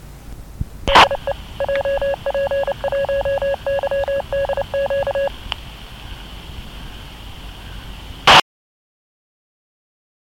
emoji1990年頃、カセットテープに録音したJP1YDQのID。
emoji439.70MHz JP1YDQのID（1990年?月?日、?時??分、自宅で録音）